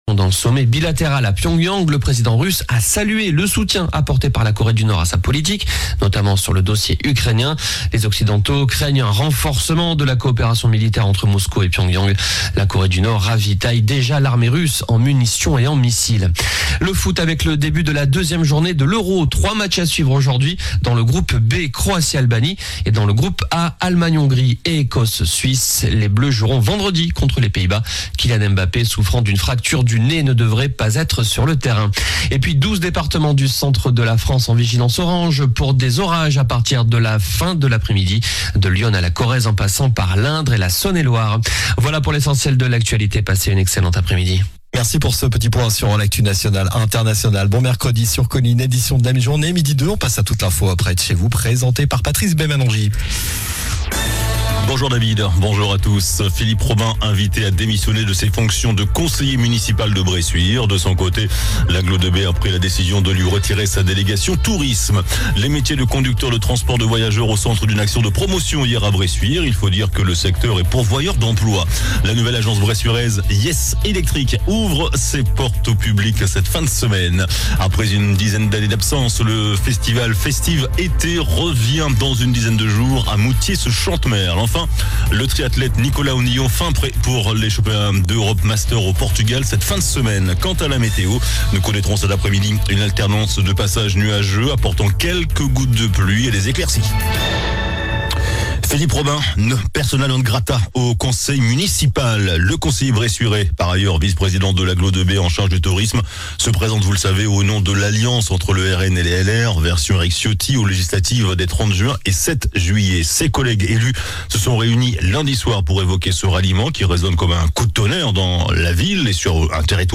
JOURNAL DU MERCREDI 19 JUIN ( MIDI )